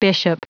Prononciation du mot bishop en anglais (fichier audio)
Prononciation du mot : bishop